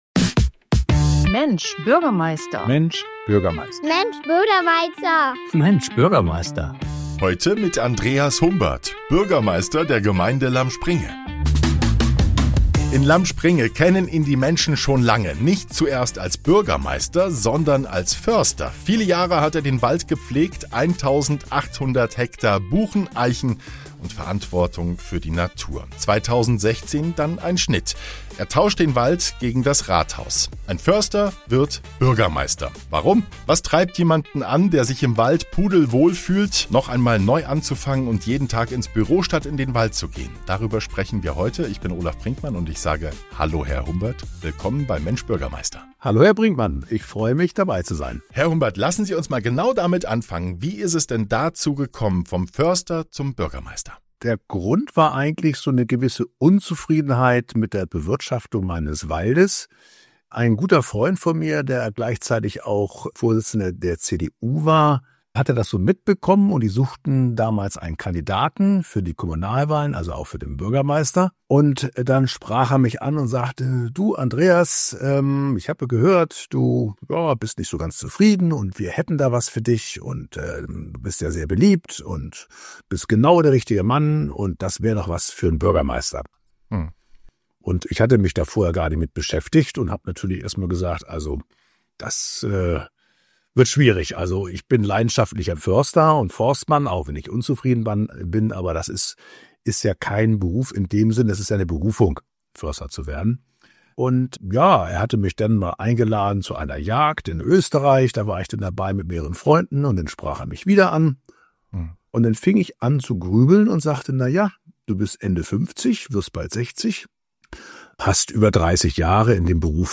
In dieser Episode von „Mensch, Bürgermeister!“ ist der Bürgermeister der Gemeinde Lamspringe zu Gast. Er spricht über seinen ungewöhnlichen Weg ins Amt.